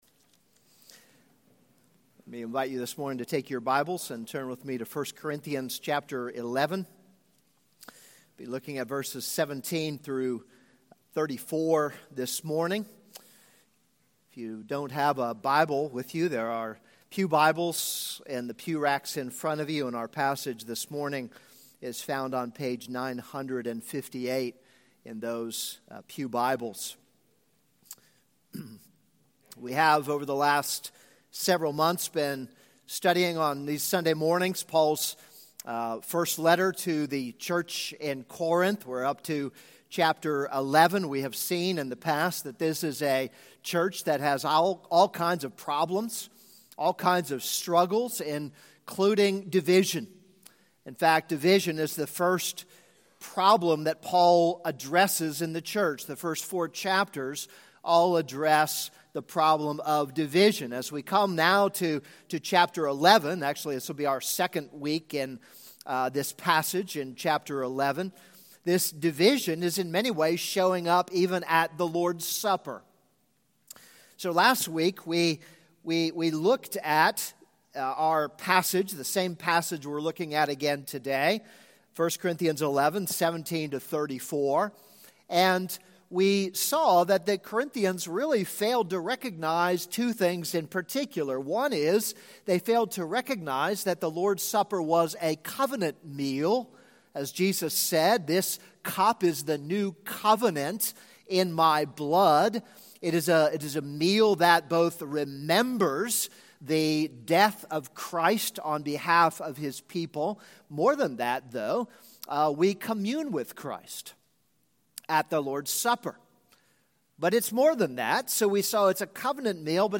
This is a sermon on 1 Corinthians 11:17-34.